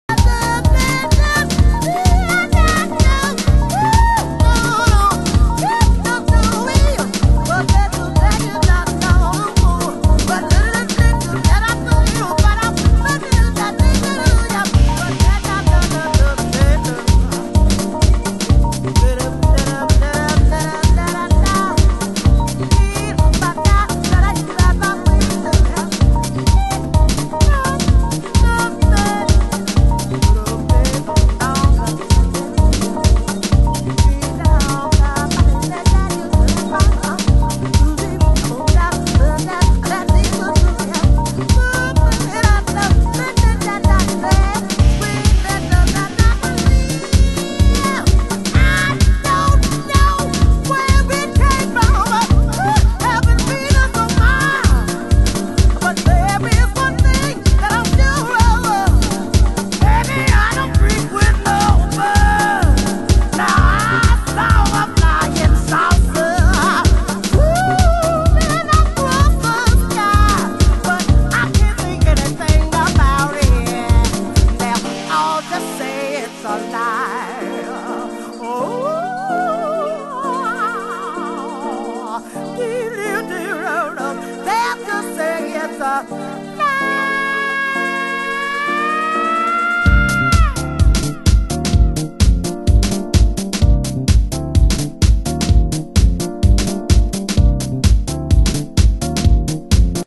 盤質：A1中盤に傷ノイズ 有/少しチリパチノイズ有　　ジャケ：底部消耗